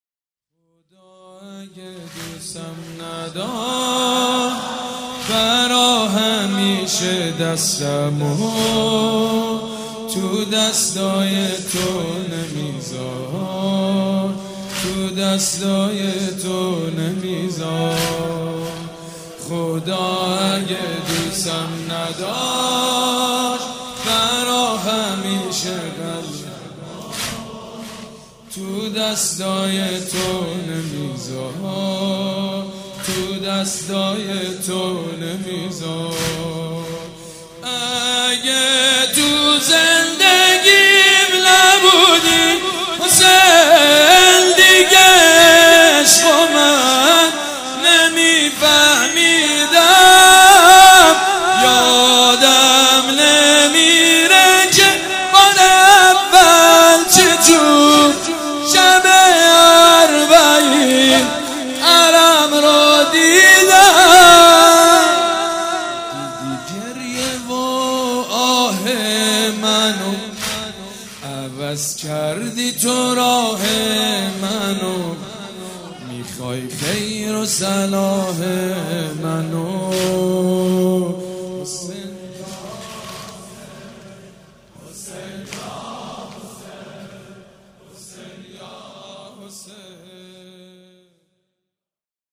سبک اثــر واحد مداح حاج سید مجید بنی فاطمه
مراسم عزاداری شب ششم